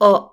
_ or